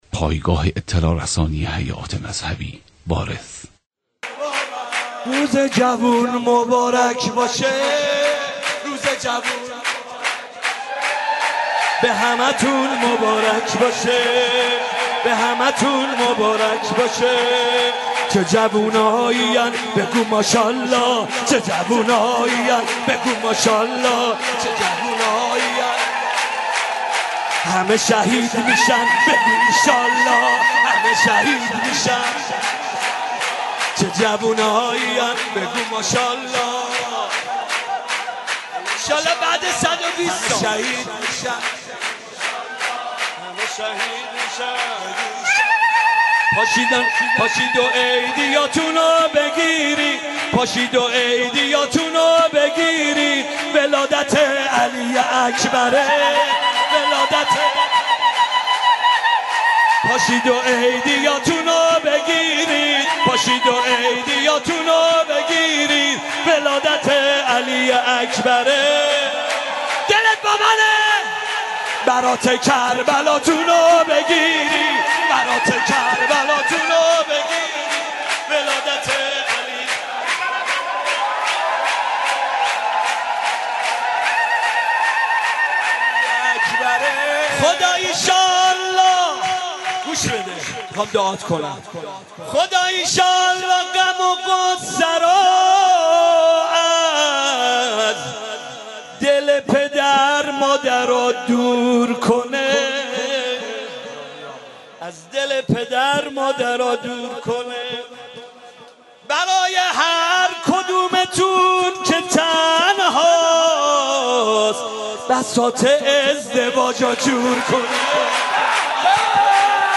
مولودی
هیئت رایة العباس ع